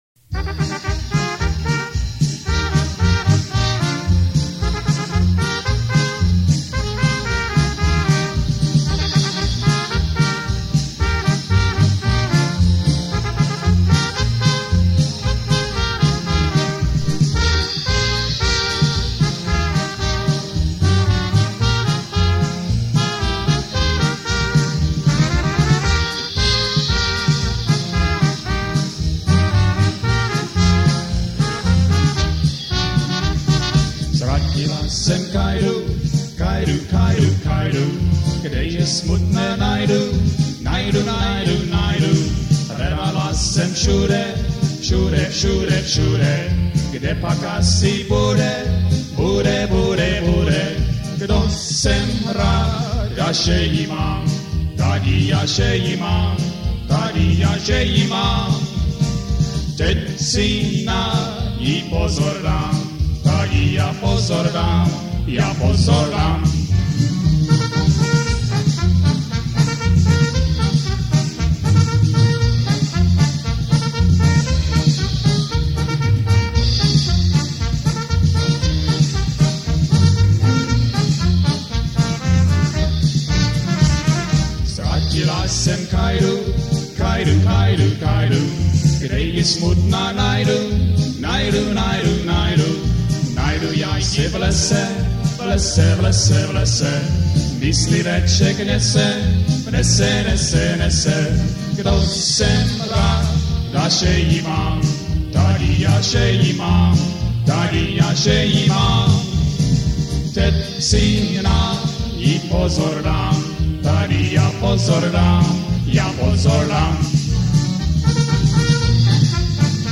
Commentary 8.